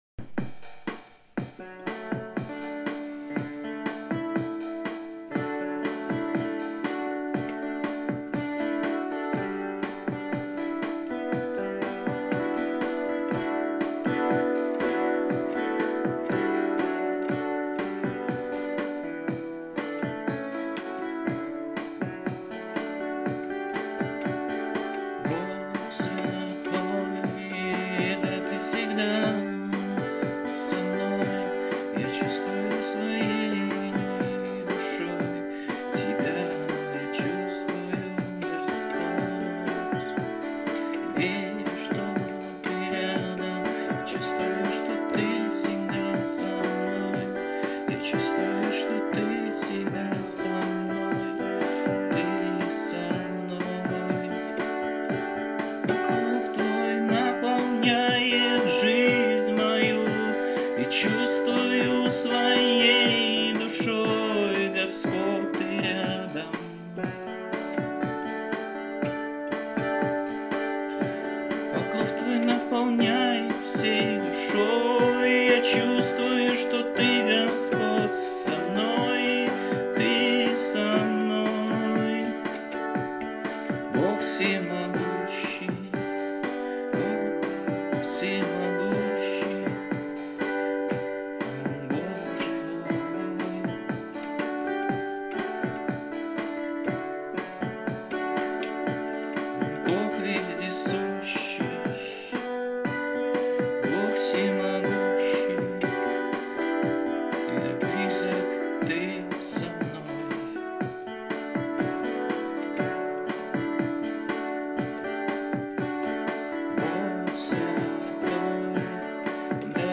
57 просмотров 86 прослушиваний 0 скачиваний BPM: 120